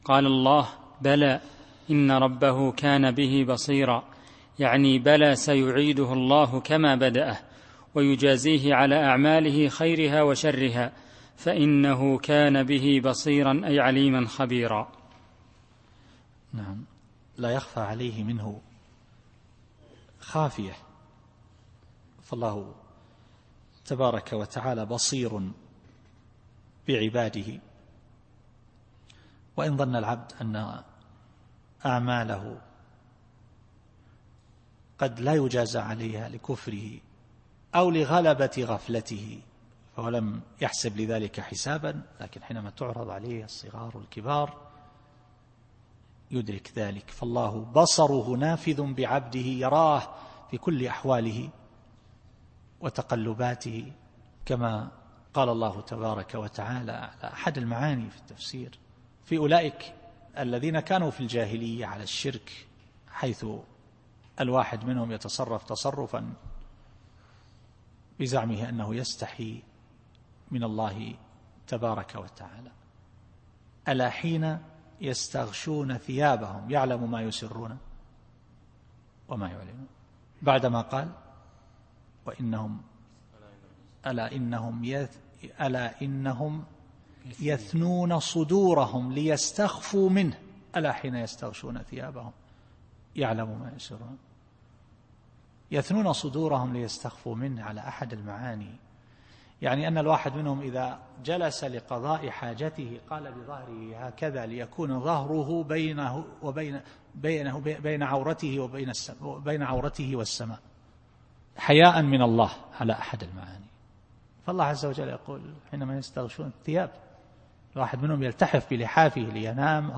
التفسير الصوتي [الانشقاق / 15]